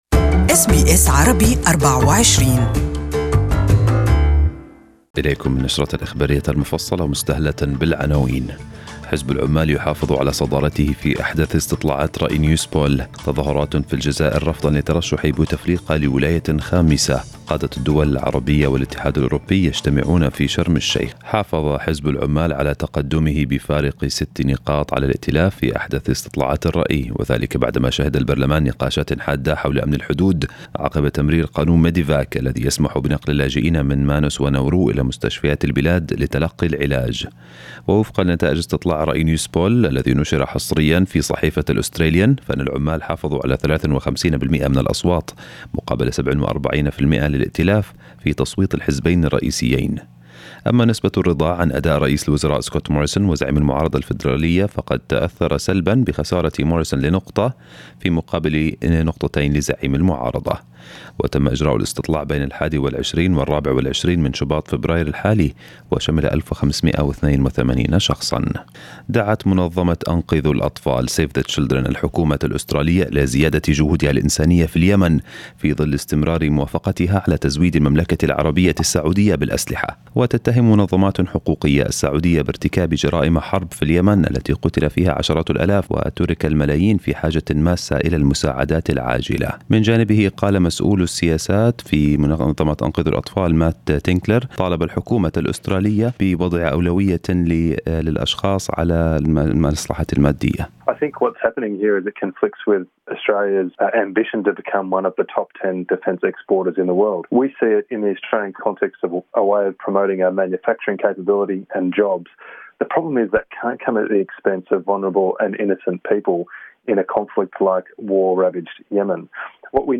Morning news bulletin in Arabic from SBS Arabic24